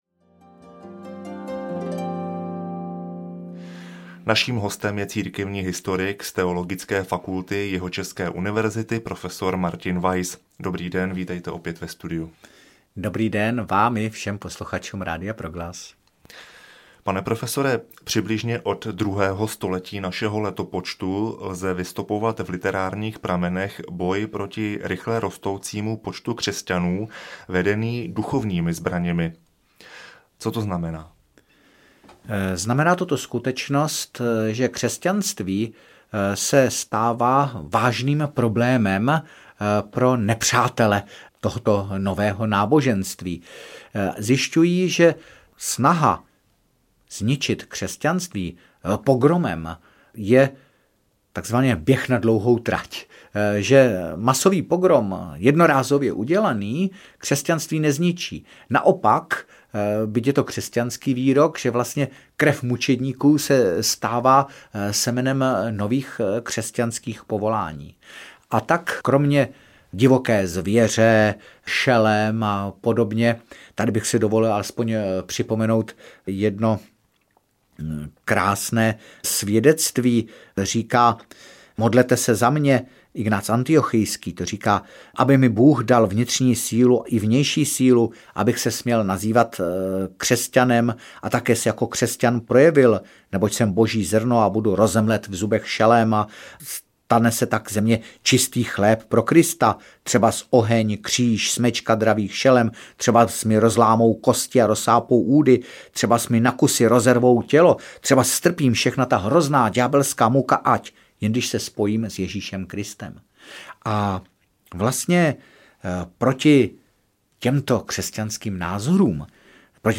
Církev a věda audiokniha
Ukázka z knihy